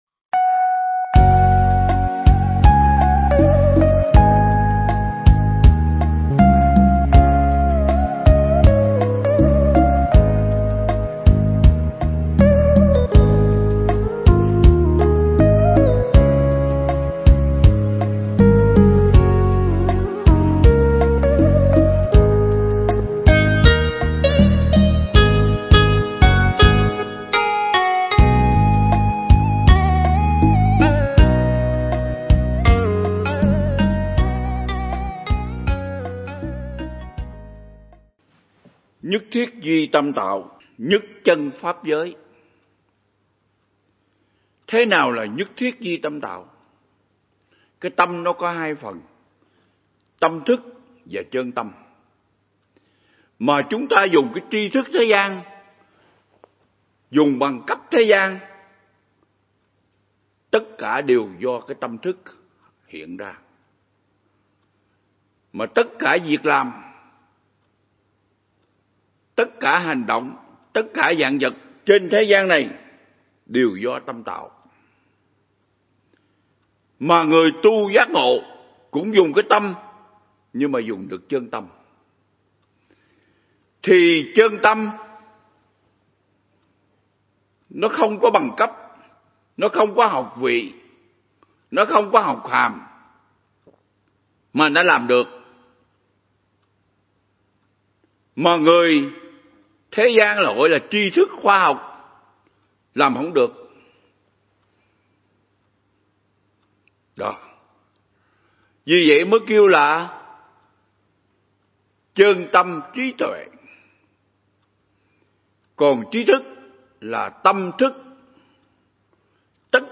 Mp3 Pháp Thoại Ứng Dụng Triết Lý Hoa Nghiêm Phần 14
giảng tại Viện Nghiên Cứu Và Ứng Dụng Buddha Yoga Việt Nam (TP Đà Lạt)